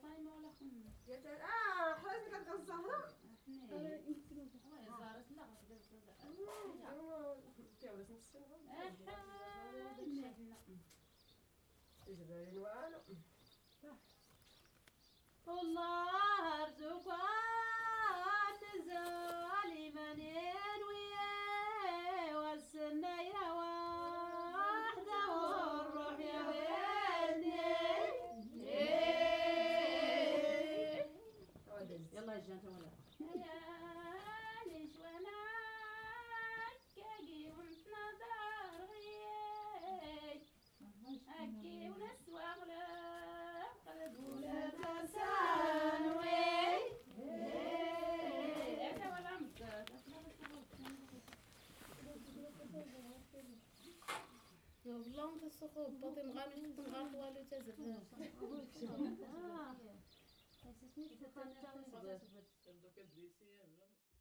31/12/2023 15:00 J’ai pris conscience de la présence des femmes en les entendant chanter.
Tout en travaillant, elles parlent, rient, et très souvent, chantent en cœur.